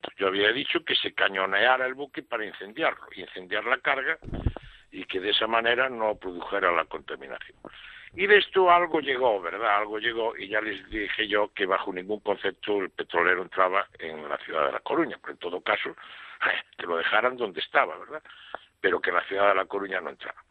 Francisco Vázquez, que hace diez años era alcalde de A Coruña, manifestó su opinión en el programa Voces de A Coruña de Radio Voz, sobre la conversación inédita en la que se muestra cómo Fomento valoró la posibilidad de meter el «Prestige» en A Coruña.